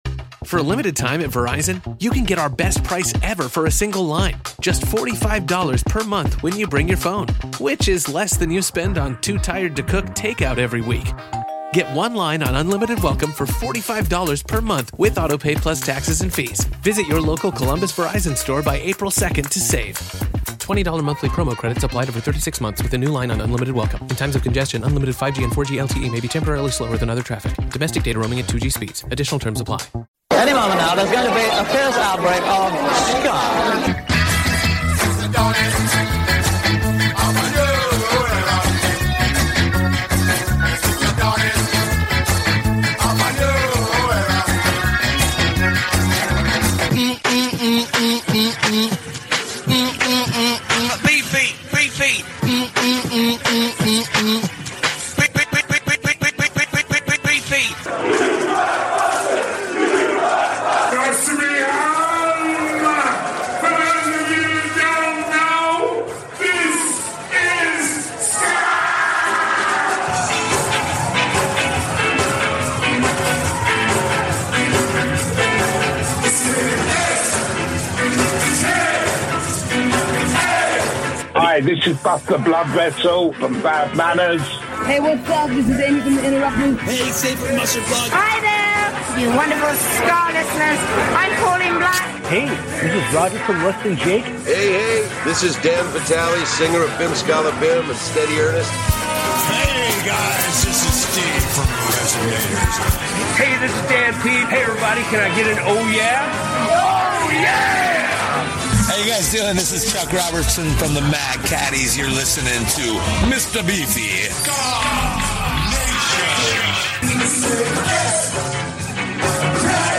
THE WORLD'S #1 SKA SHOW